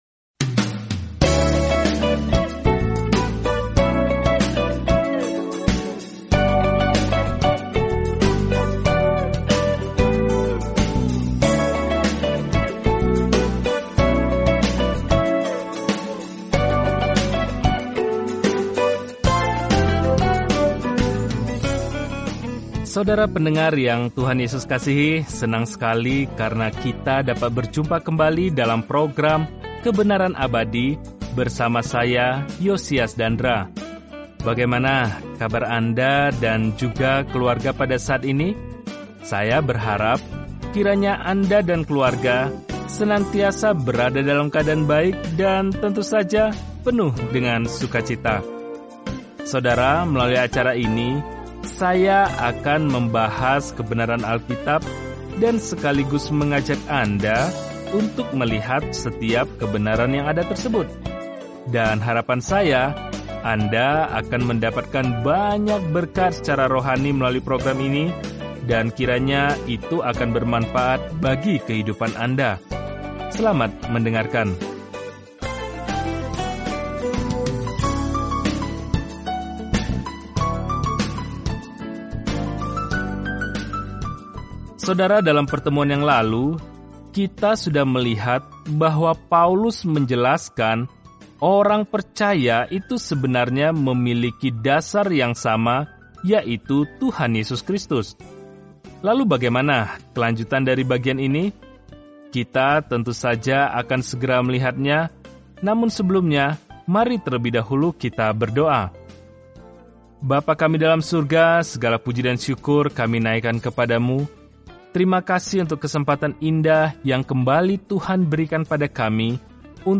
Apakah topik tersebut dibahas dalam surat pertama kepada jemaat di Korintus, memberikan perhatian praktis dan koreksi terhadap permasalahan yang dihadapi kaum muda Kristen. Telusuri 1 Korintus setiap hari sambil mendengarkan pelajaran audio dan membaca ayat-ayat tertentu dari firman Tuhan.